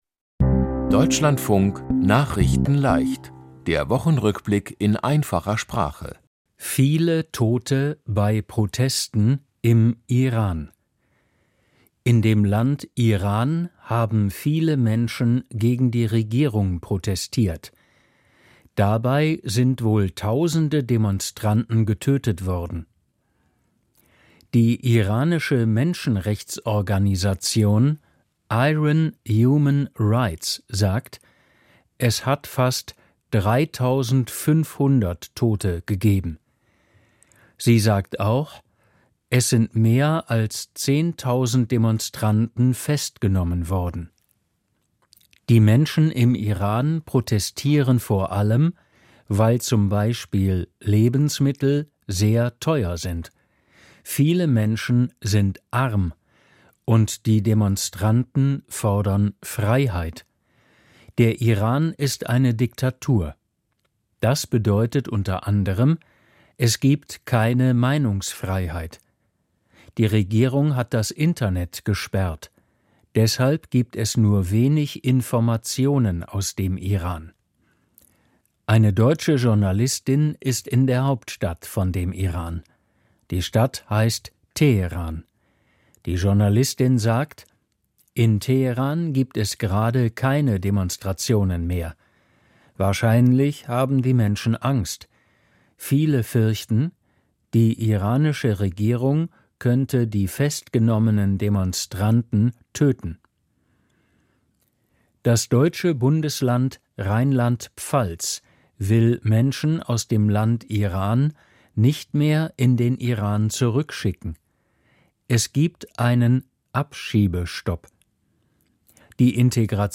Die Themen diese Woche: Viele Tote bei Protesten im Iran, Streit um die Insel Grönland: Europäische Staaten schicken Soldaten, Viele Probleme durch Glatt-Eis, Film-Preise "Golden Globes" überreicht und "Sonder-Vermögen" ist das Unwort des Jahres. nachrichtenleicht - der Wochenrückblick in einfacher Sprache.